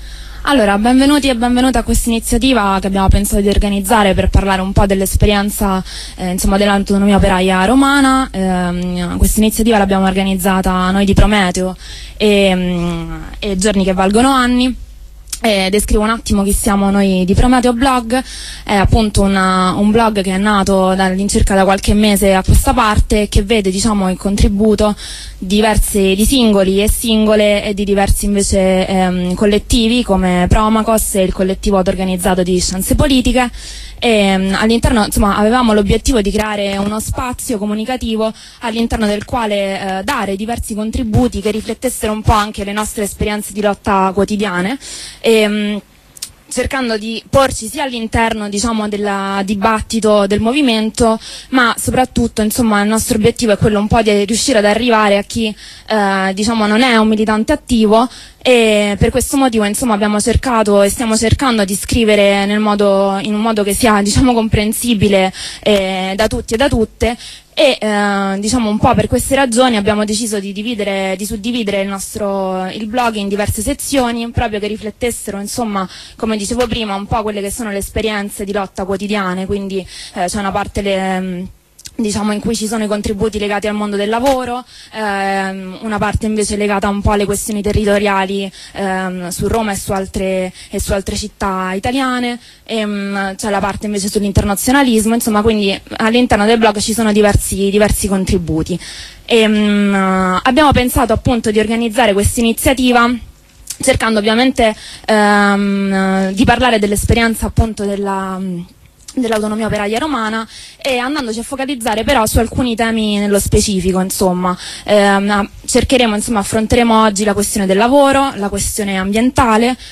Podcast degli interventi e del dibattito dell'iniziativa "Autonomia all'assalto del cielo" a VIII Zona (via Lussimpiccolo 19/17), promossa da Radio Onda Rossa, Prometeoblog e Giorni che valgono anni.